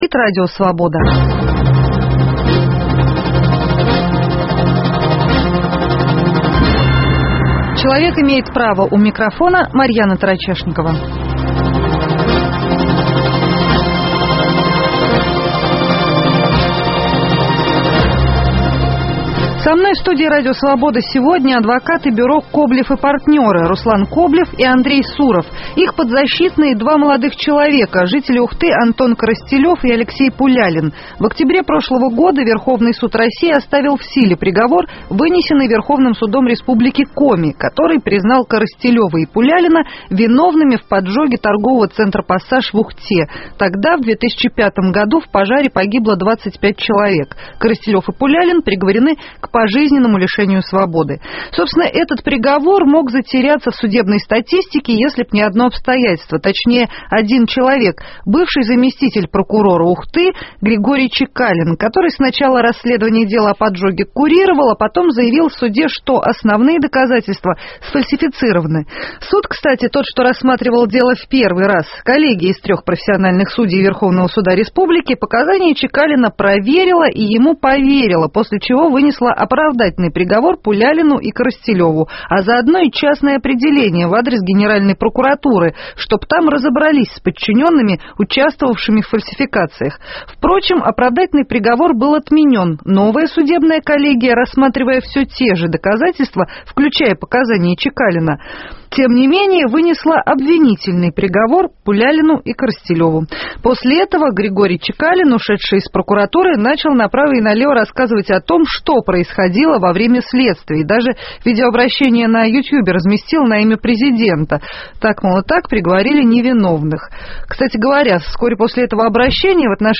В студии РС адвокаты